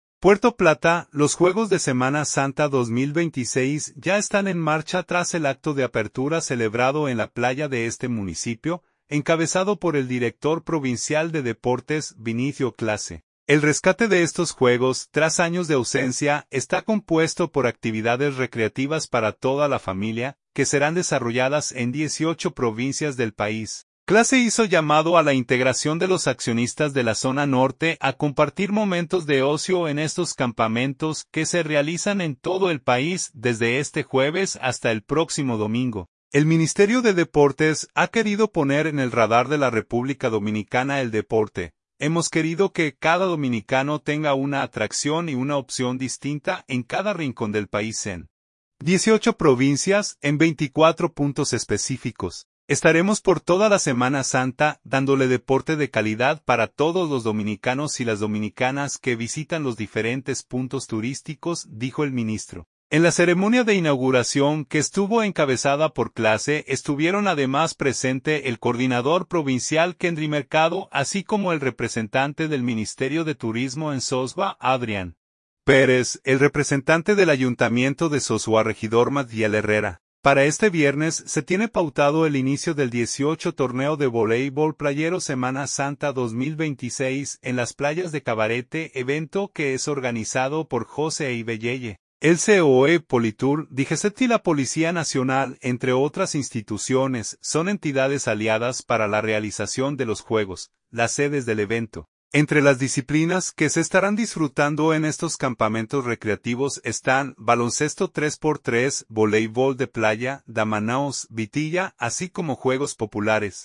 acción de Voleibol de playa